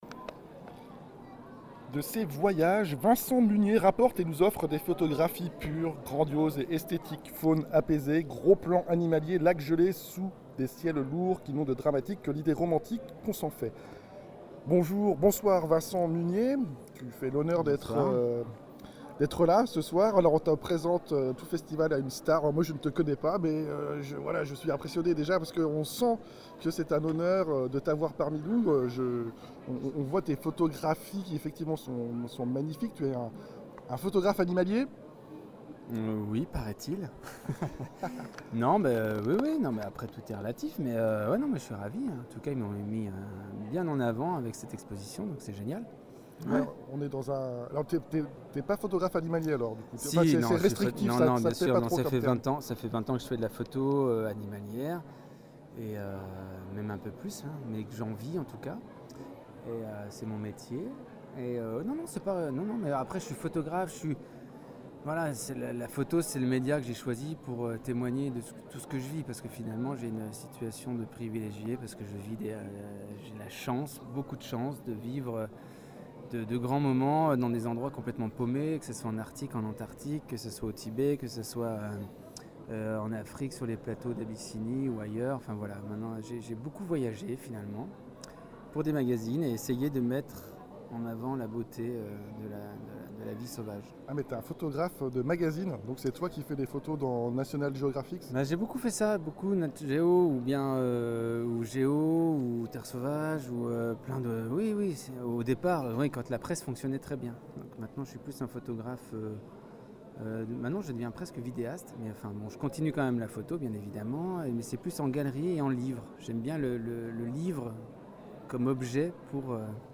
Interview
Confrontations Photos GEX 12-14 Oct 2018